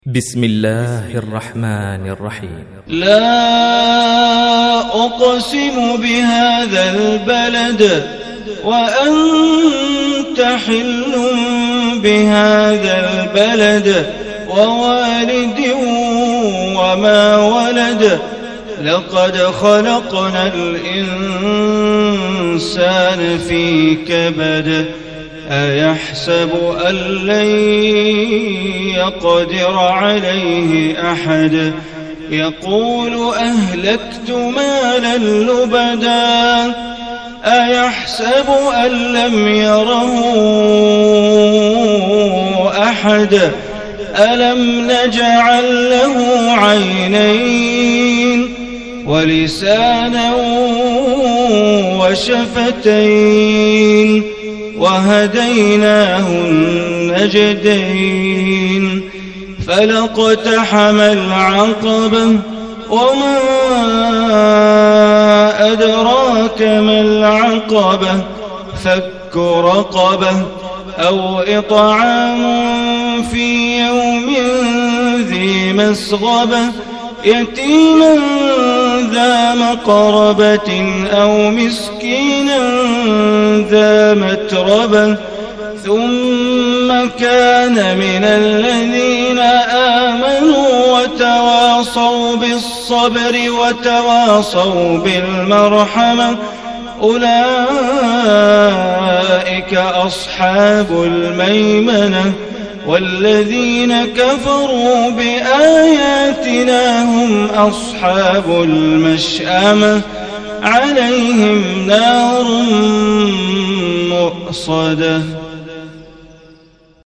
Surah Al-Balad MP3 Recitation by Bandar Baleela